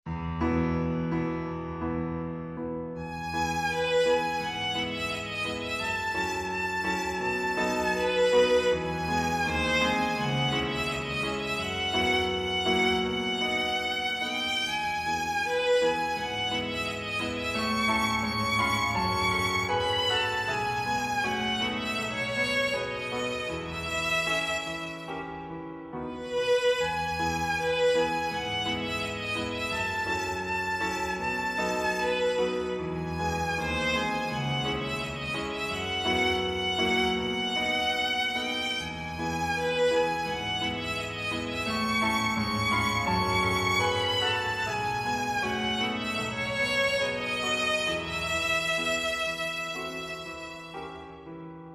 クラシック音楽の曲名
チャ～ ⤴　チャラ　チャラ　チャラ　ラ ⤴ ～ン　ラ ⤴ ～ン　ラ ⤴ ～ン　ンー